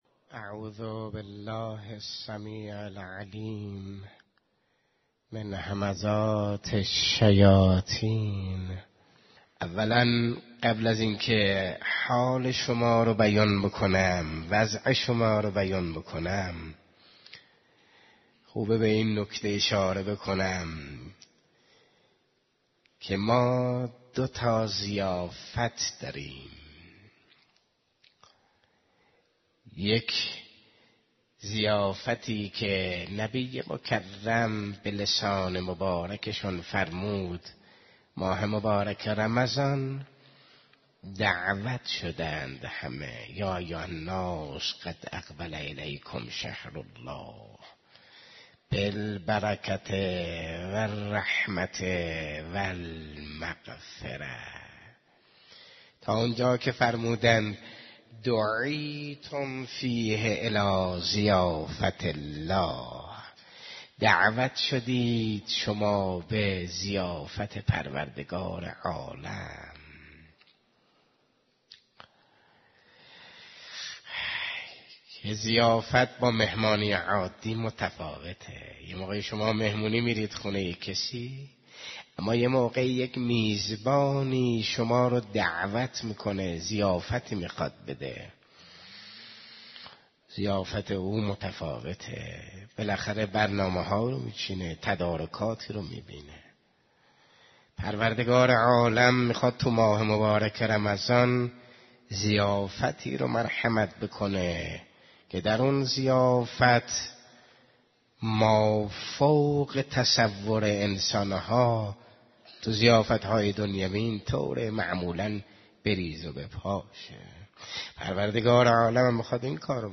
صوت| سخنرانی های روز دوم اعتکاف رجبیه 1395 – (اختصاصی هیأت)